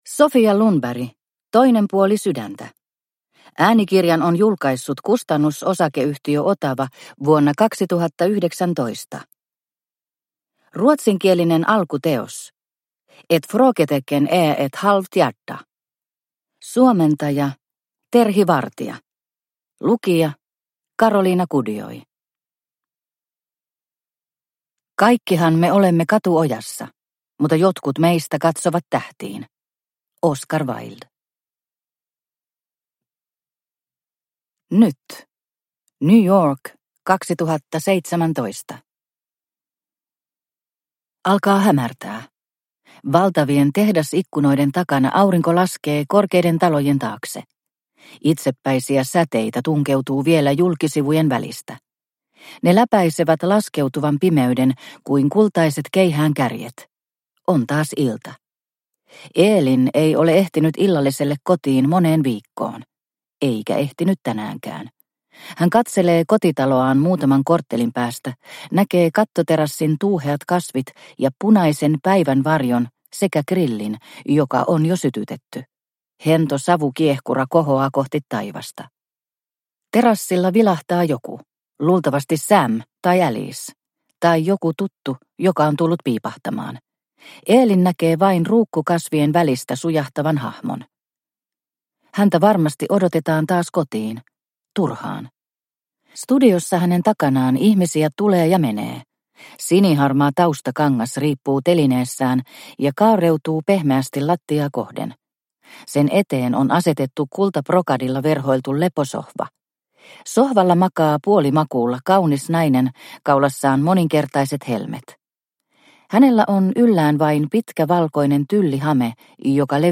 Toinen puoli sydäntä – Ljudbok – Laddas ner